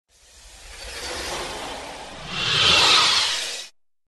PWR_ON.mp3